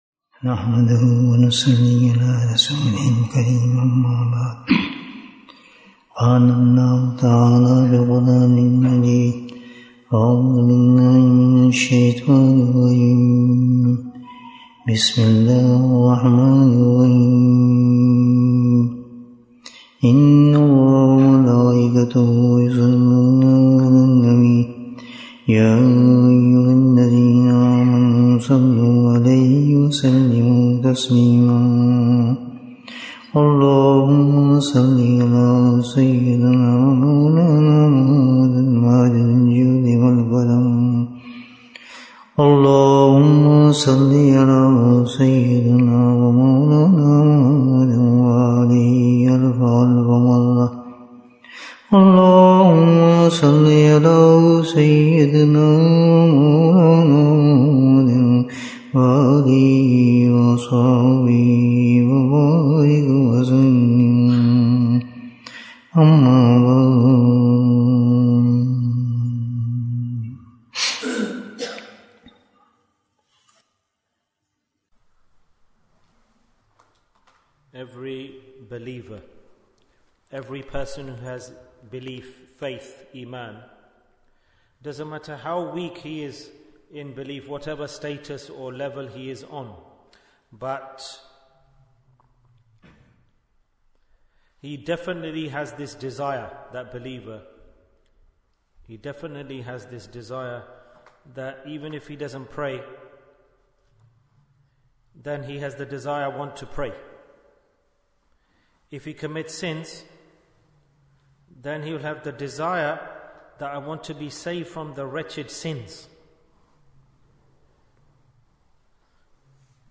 Bayan, 82 minutes31st October, 2024